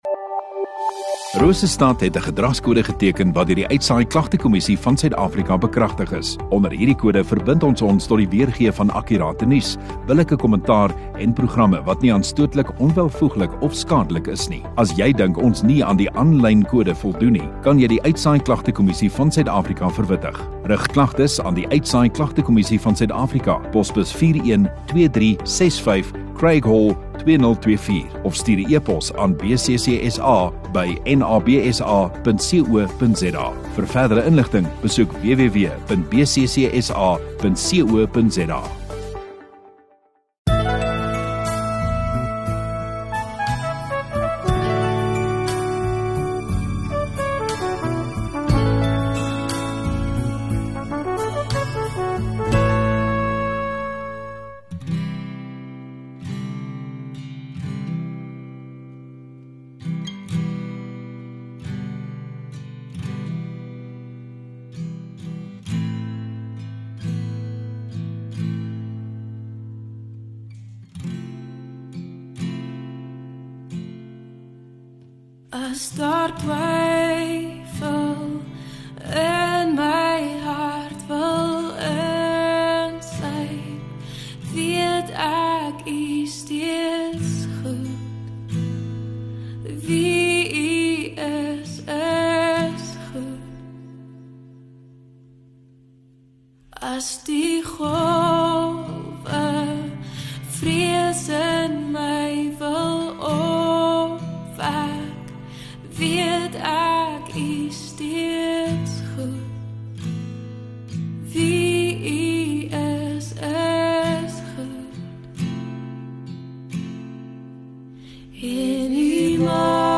23 Mar Sondagaand Erediens